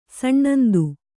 ♪ saṇṇandu